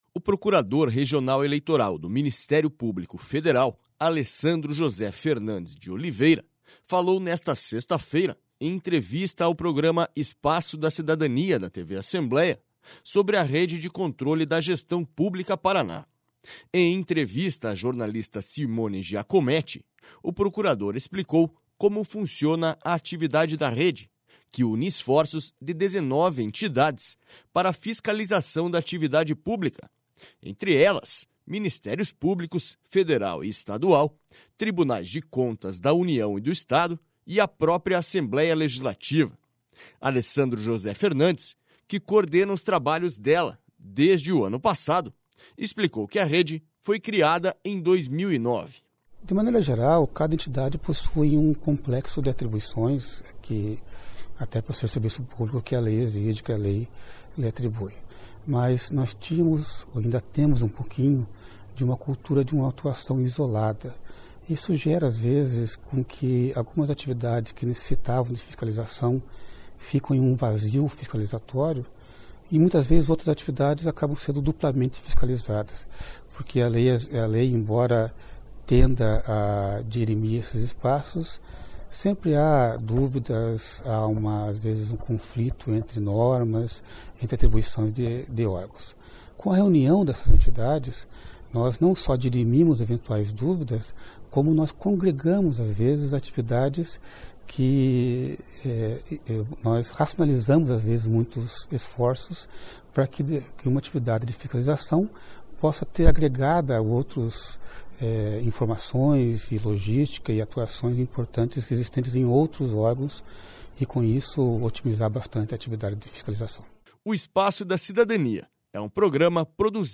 SONORA ALESSANDRO JOSÉ FERNANDES DE OLIVEIRA